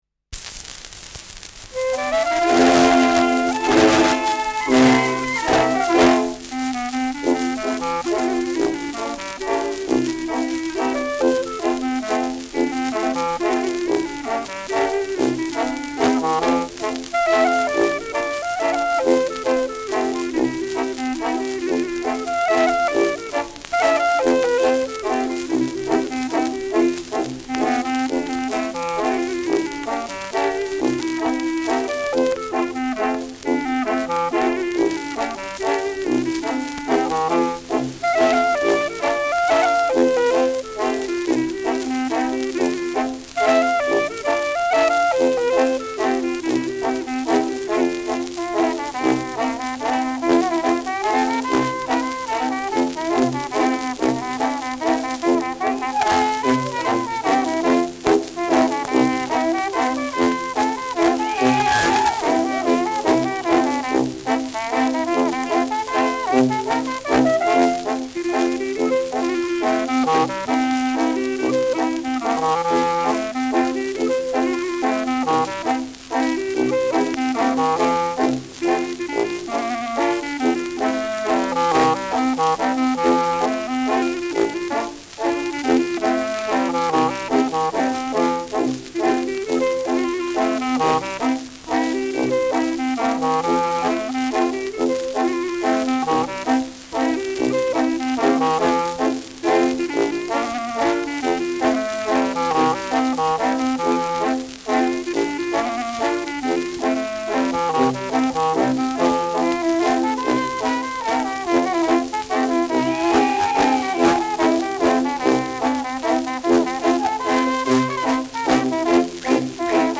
Schellackplatte
präsentes Knistern : präsentes Rauschen : leichtes Leiern
Dachauer Bauernkapelle (Interpretation)
[München] (Aufnahmeort)